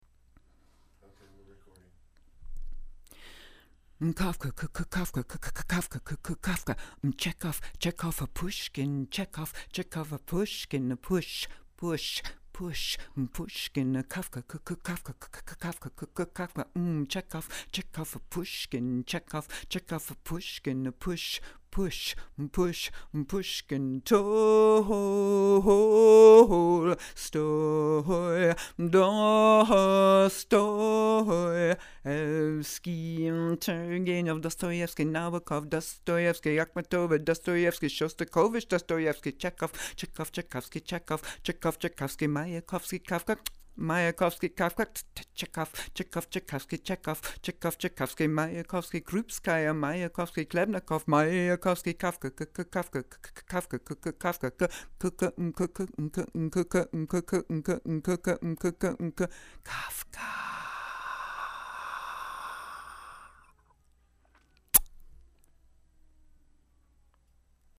Russian Percussion plus Kafka
russian_percussion_1.mp3